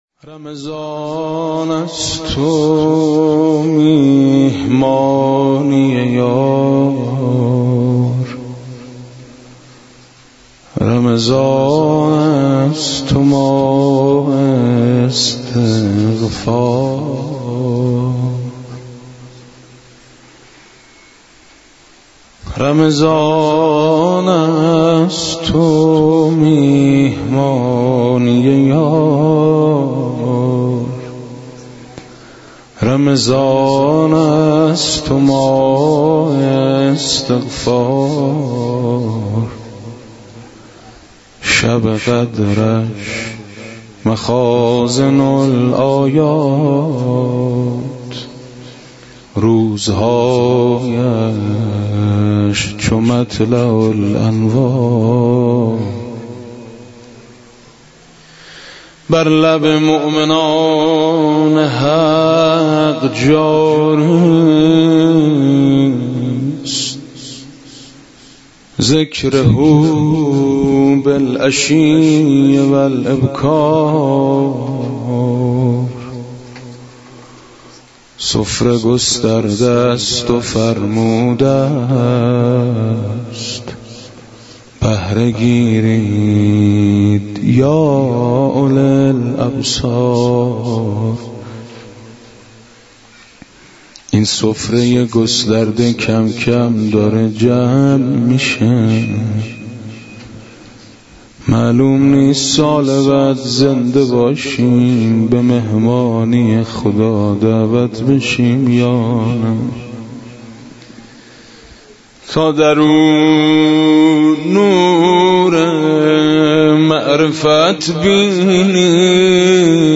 مراسم شب 27رماه مبارک رمضان